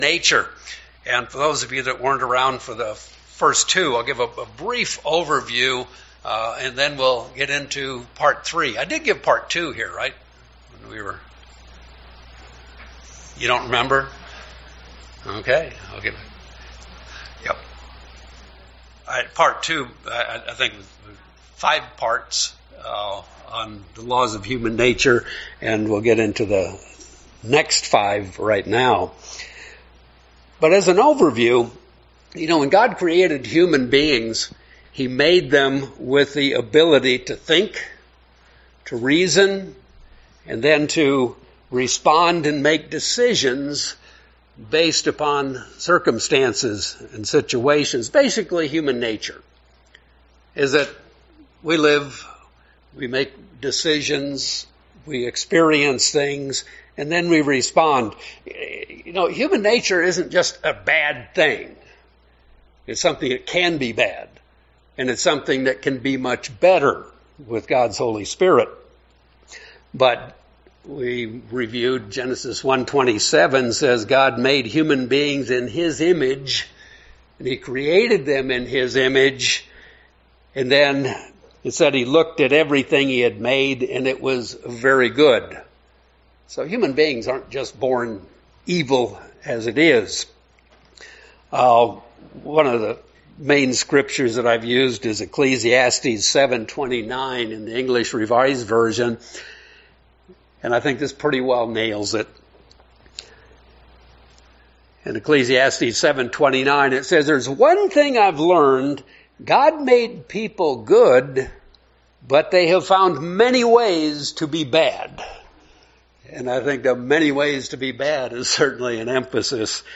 We finish our exploration of the good, bad, and ugly aspects of Human nature. We see that by understanding the Laws of Human nature, we can use God's spirit to become more like Christ. This sermon is the third and final installment of a 3 part sermon series.